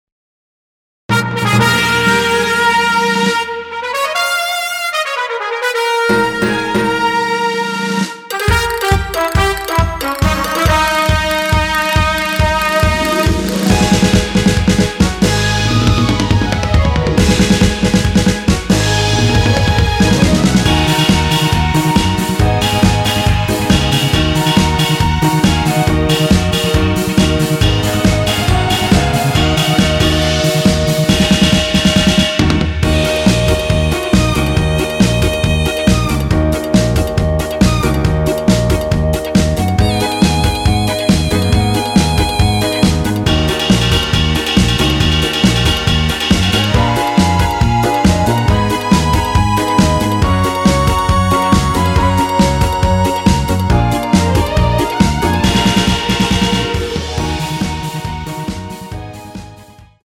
원키 멜로디 포함된 MR입니다.
Ebm
앞부분30초, 뒷부분30초씩 편집해서 올려 드리고 있습니다.
(멜로디 MR)은 가이드 멜로디가 포함된 MR 입니다.